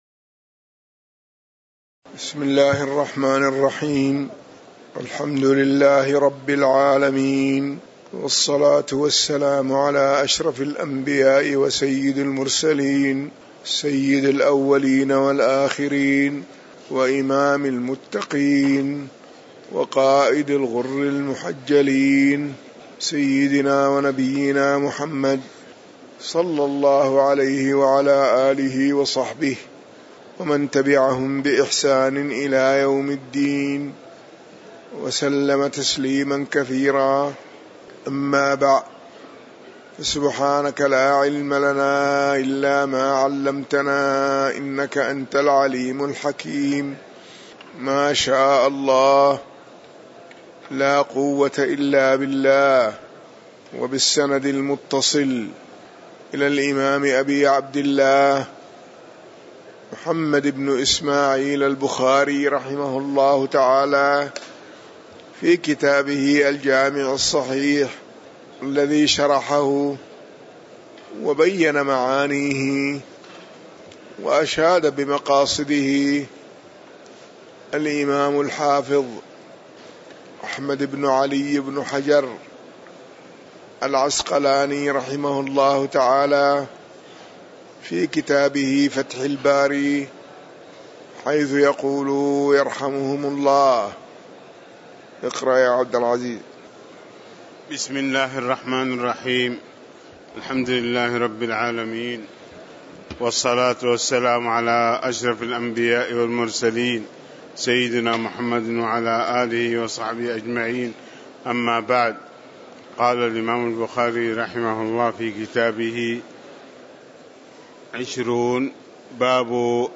تاريخ النشر ١٢ ذو القعدة ١٤٣٩ هـ المكان: المسجد النبوي الشيخ